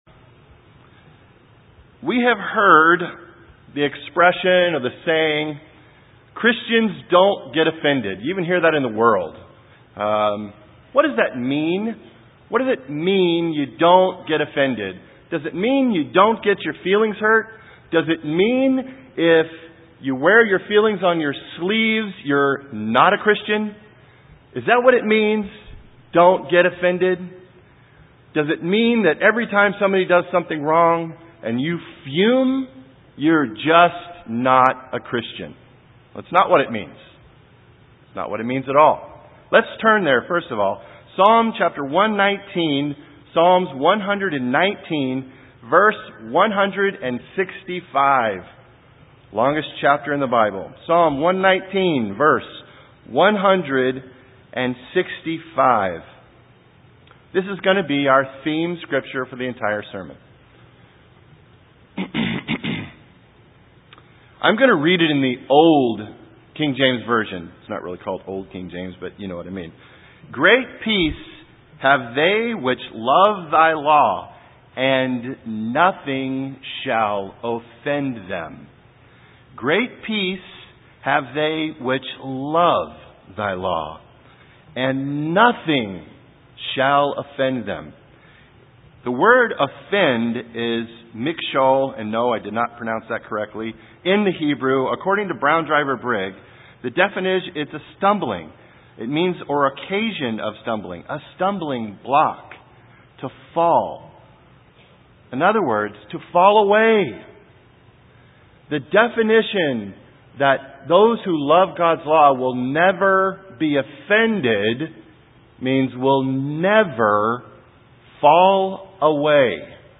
This sermon will give us the tools we need to be the Christian that keeps maintains their relationship with God and His family.